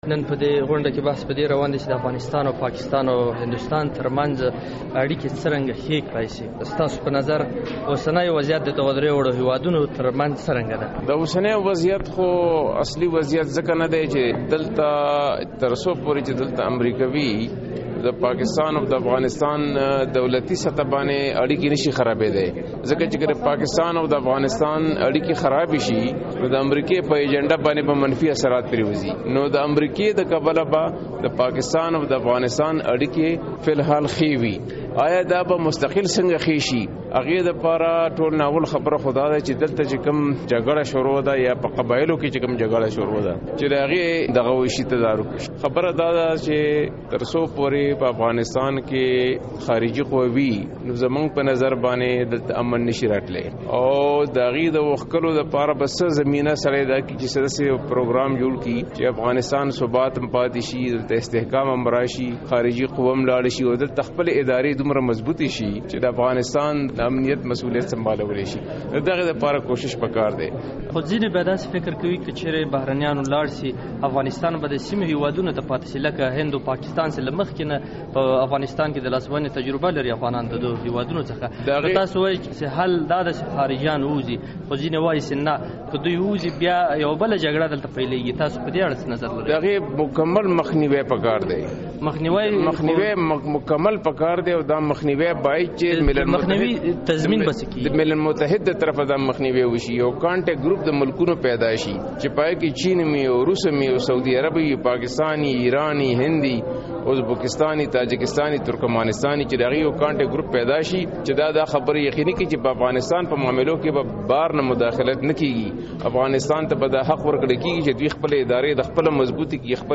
له رستم شاه مومند سره مرکه